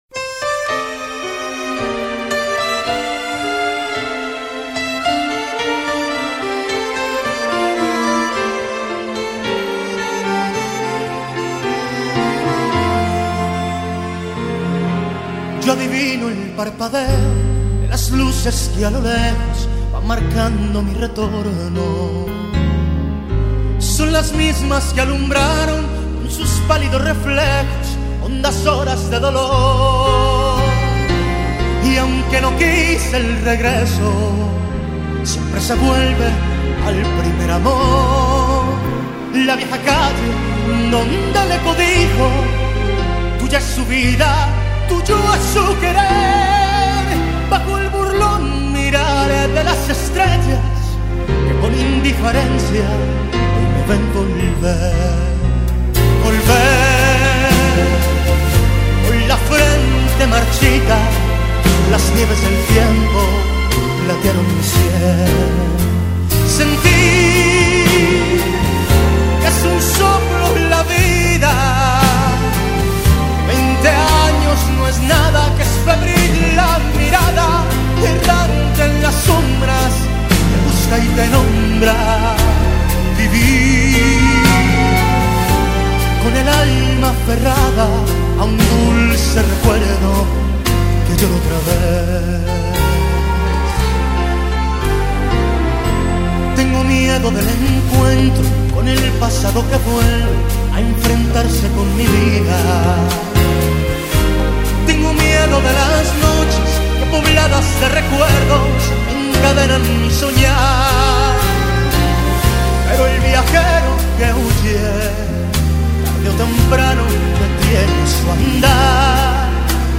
1935   Genre: Tango   Artist